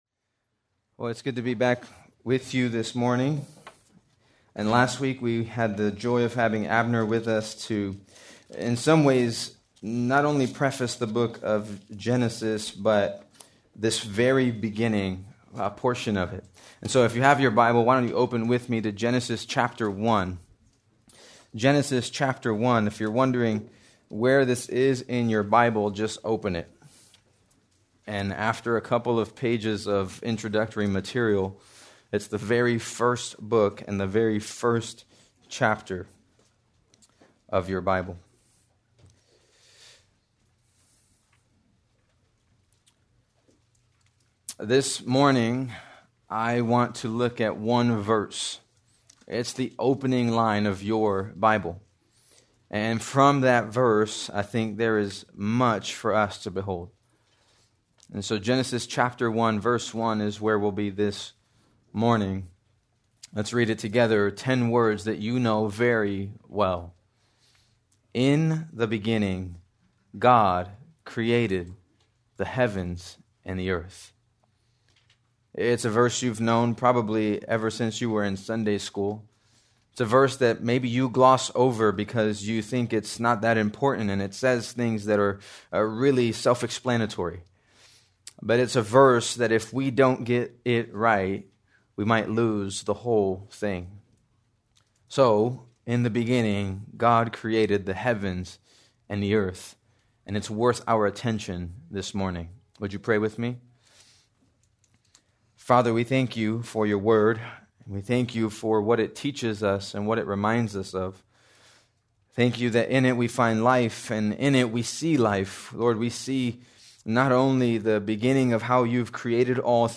January 18, 2026 - Sermon | 180 Ministry | Grace Community Church